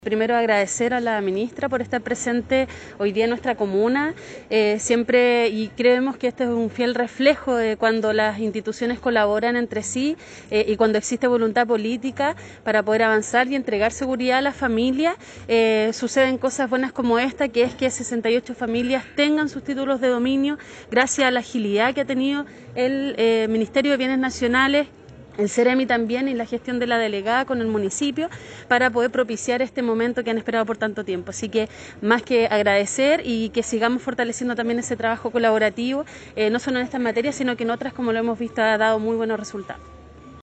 Tras una significativa ceremonia, más de 60 familias de la comuna de Melipilla recibieron sus ansiados Títulos de Dominio, en una actividad presidida por la Ministra de Bienes Nacionales, Javiera Toro; la Delegada Presidencial Provincial, Sandra Saavedra; el seremi de Bienes Nacionales, Germán Pino; y la alcaldesa Lorena Olavarría.